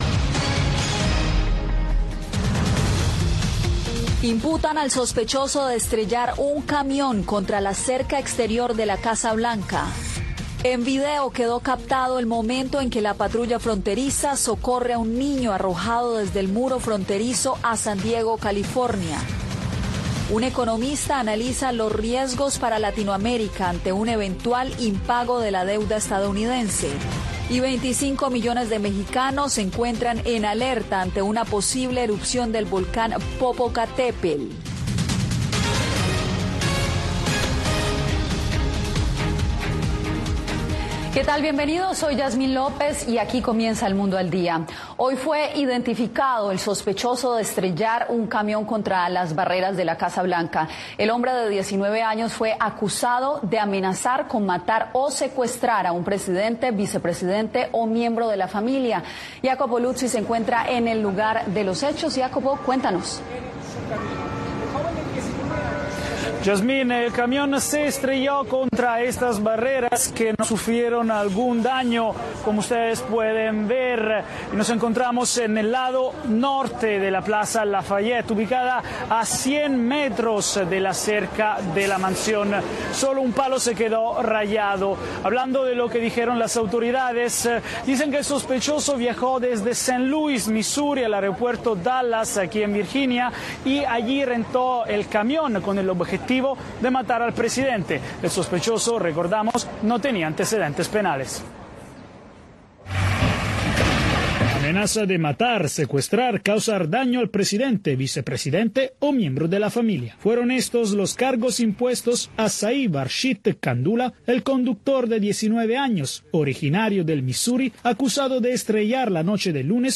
Noticiero Radio Martí presenta los hechos que hacen noticia en Cuba y el mundo.